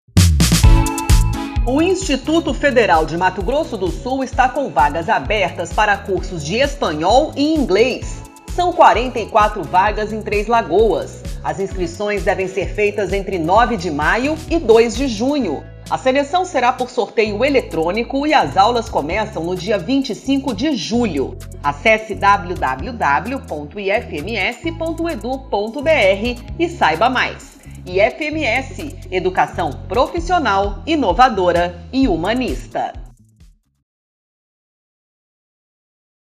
Spot - Cursos de idiomas para o 2º semestre de 2022 em Três Lagoas
Áudio enviado às rádios para divulgação institucional do IFMS.